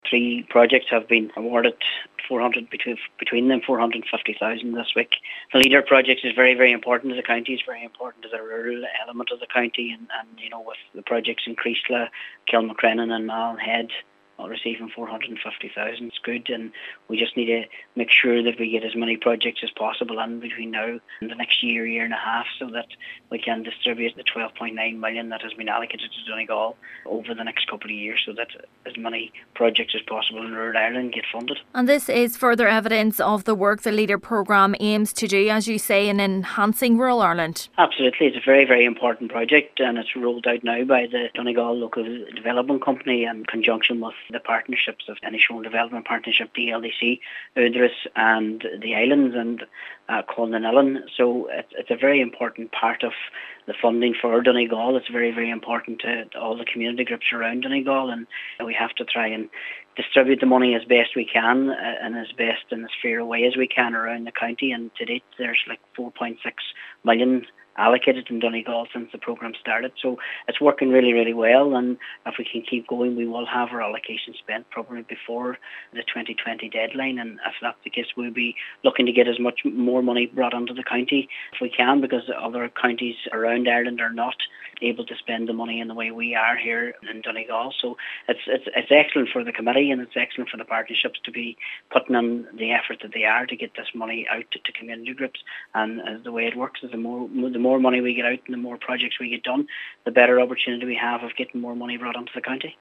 Chair of the Donegal Local Development Committee Councillor Martin McDermott has welcomed the funding, and is hopeful more funding for the county could be forthcoming…………